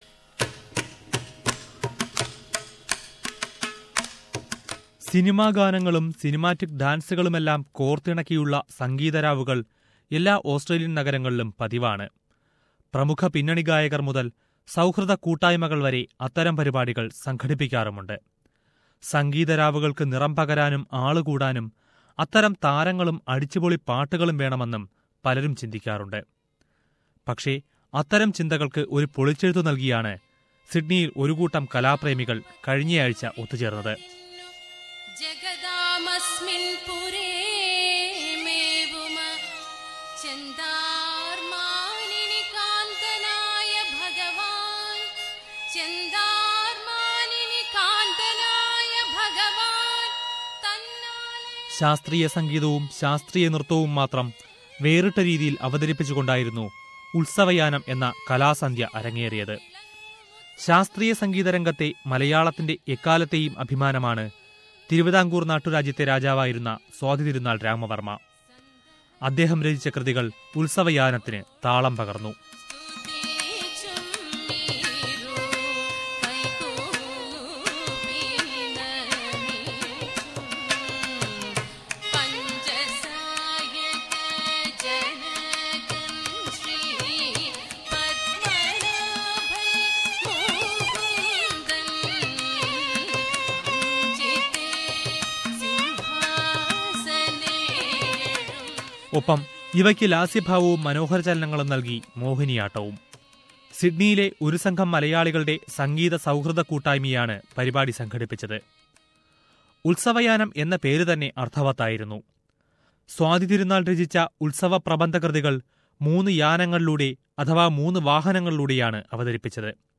Let us listen to a special report on Utsavayanam...